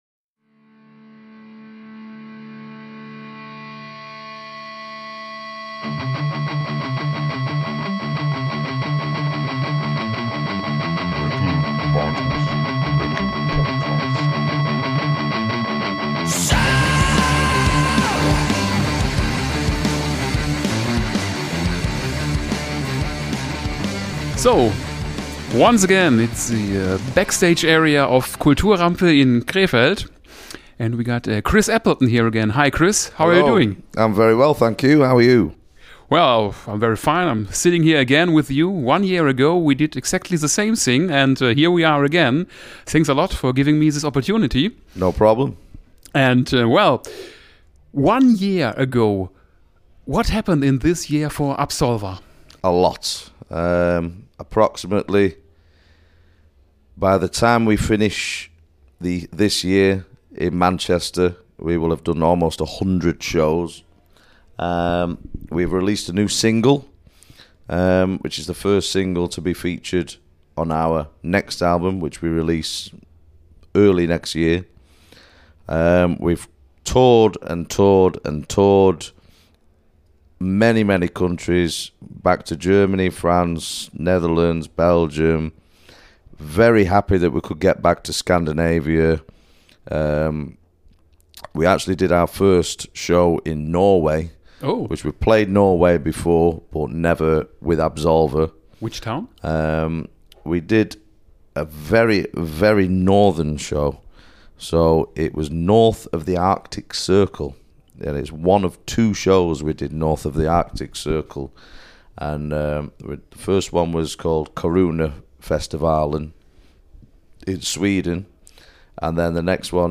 Special: Interview With Absolva (One Year Later)